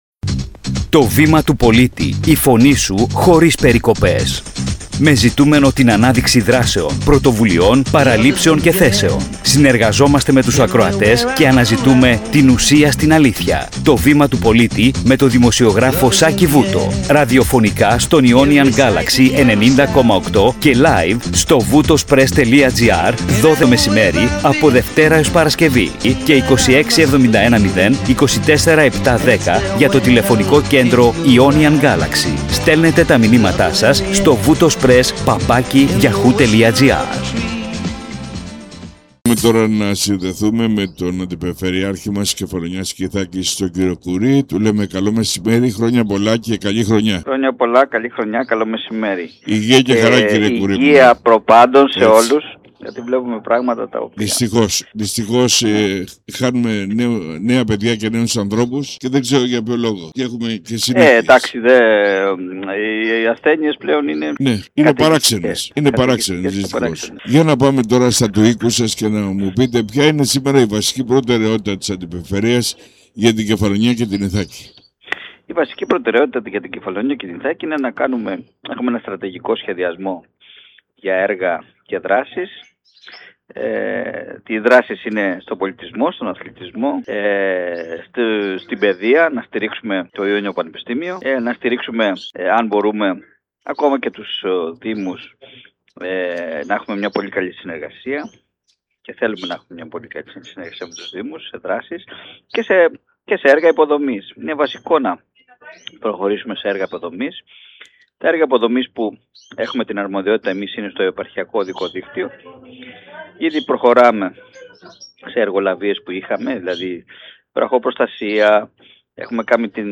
Συνέντευξη – Ερώτηση / Απάντηση
Ραδιοφωνικός σταθμός Ionian Galaxy 90.8